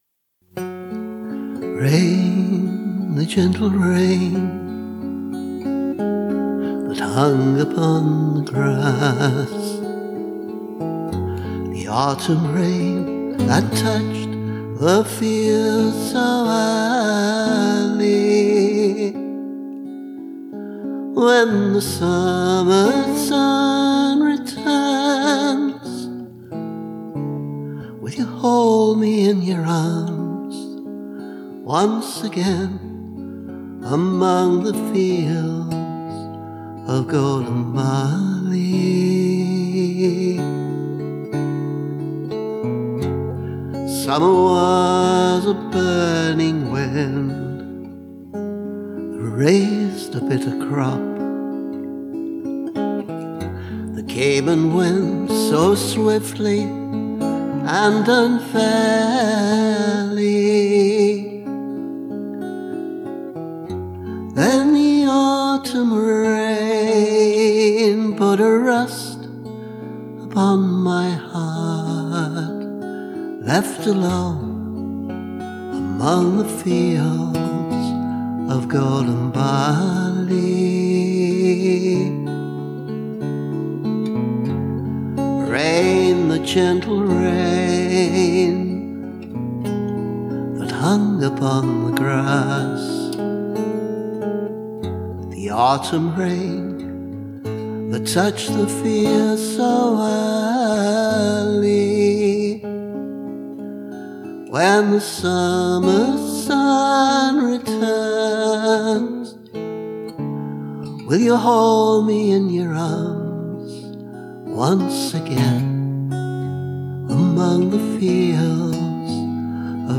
rain-gtr-3.mp3